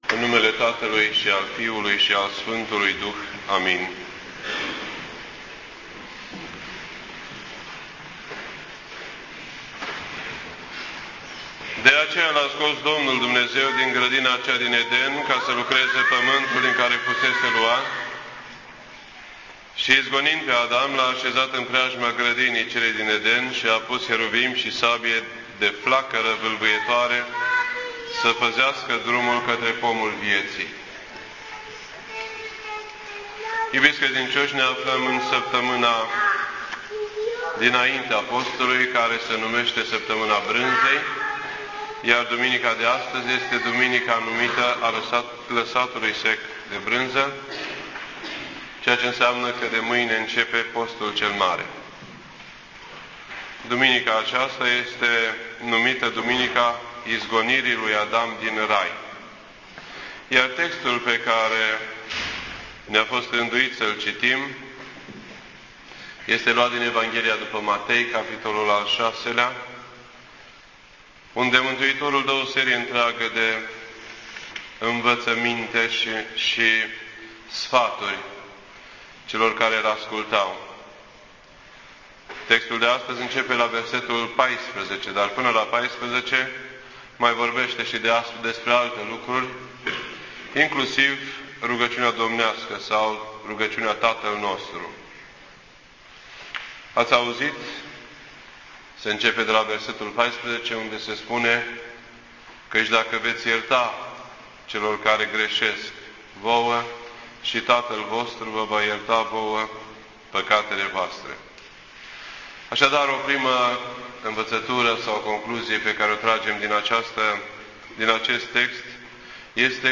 This entry was posted on Sunday, March 6th, 2011 at 9:01 PM and is filed under Predici ortodoxe in format audio.